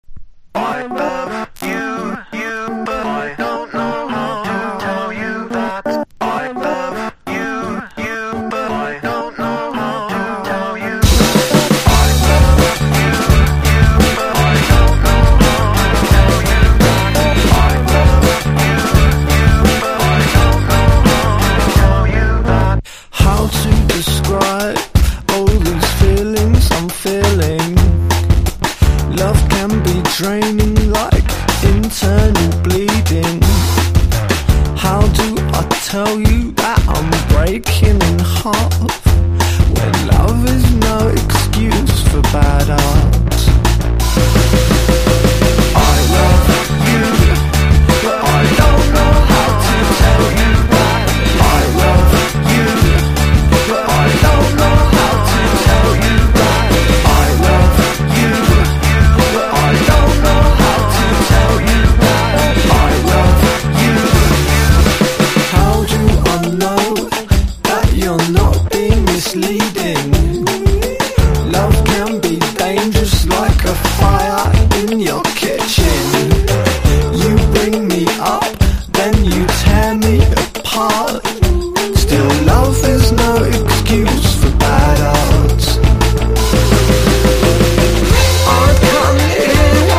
INDIE DANCE
BREAK BEATS / BIG BEAT